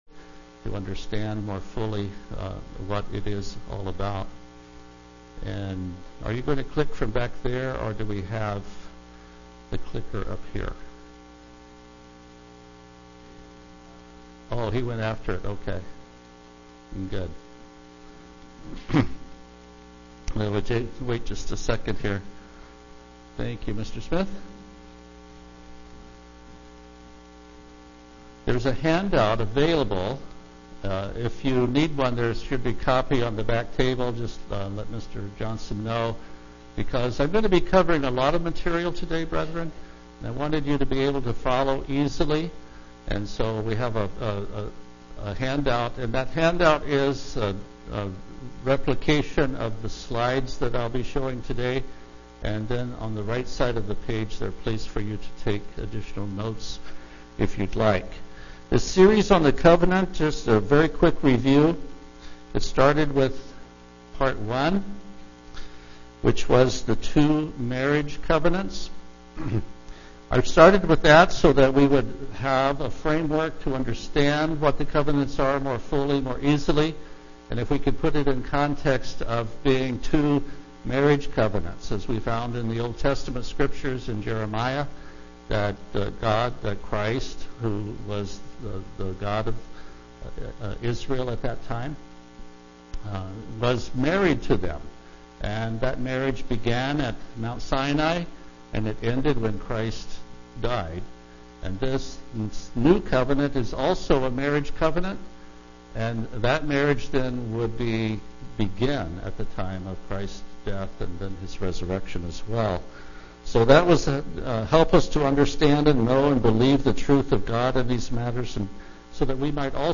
Given in Olympia, WA Tacoma, WA
UCG Sermon Studying the bible?